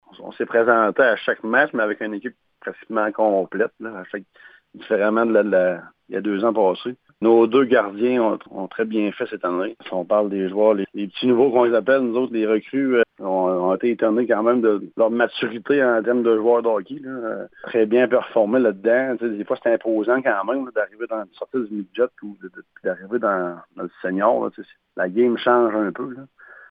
L’entraineur avait de bons mots pour ses joueurs :